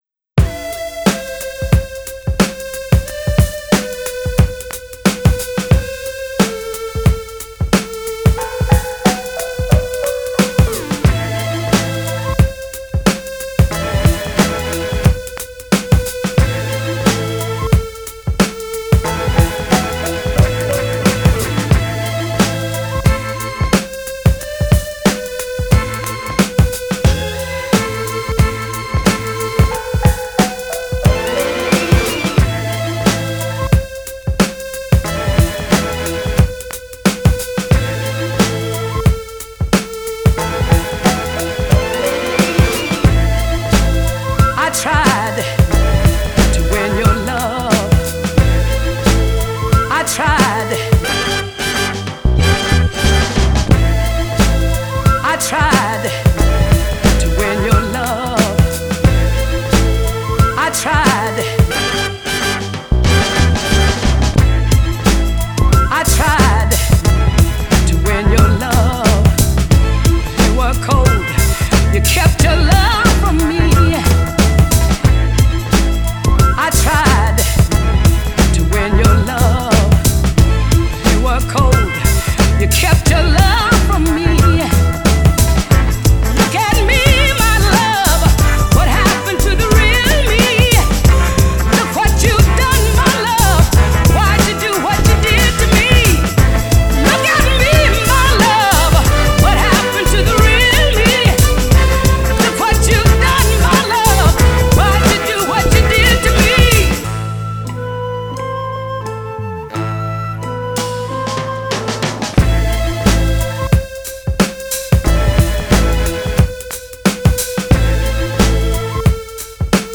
the haunted Northern